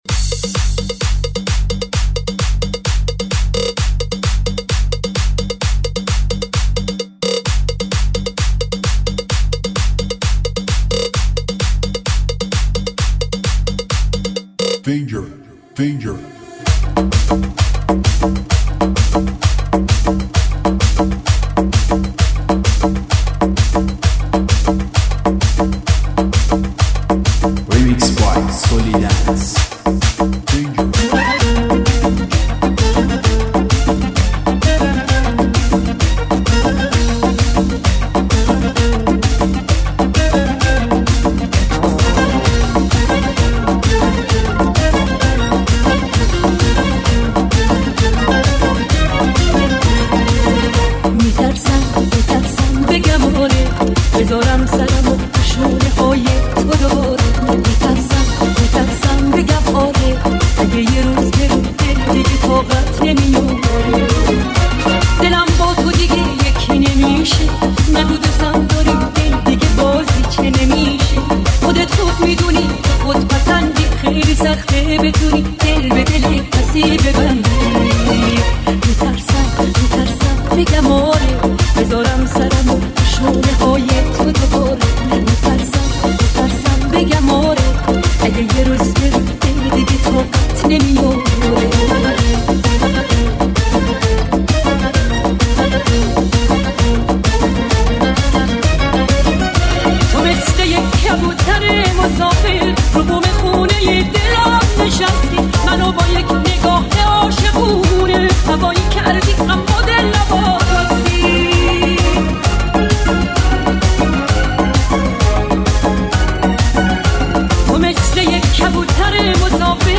ریمیکس باشگاهی ، ریمیکس بندری